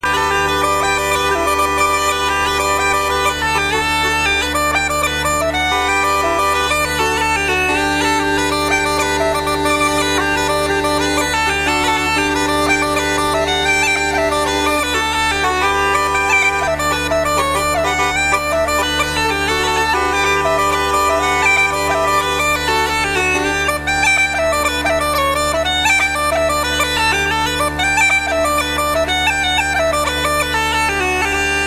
Fiddle
Guitar
Mandocello & Keyboards
Piano & Keyboards